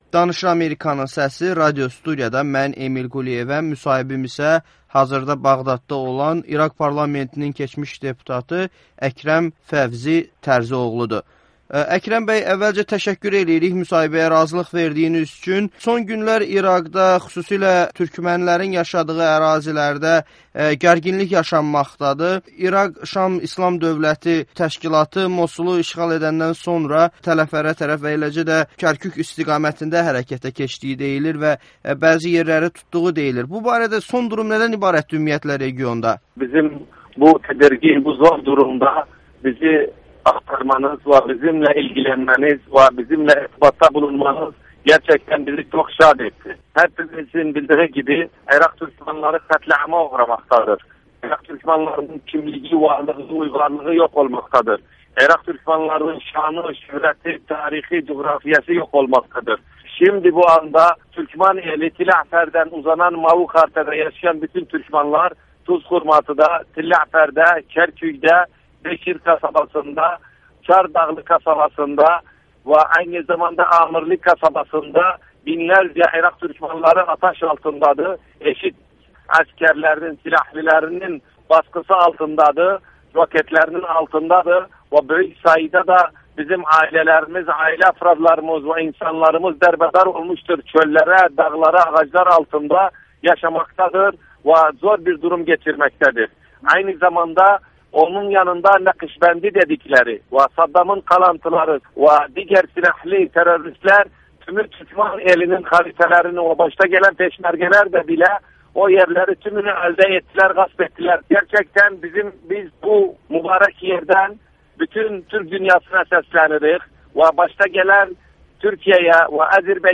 Əkrəm Fevzi: Hazırda türkmənlər İŞİD-in raketləri altındadır [Audio-müsahibə]
Əkrəm Fevzi İŞİD-in İraqdakı türkmənlərə hücumundan danışır- Müsahibə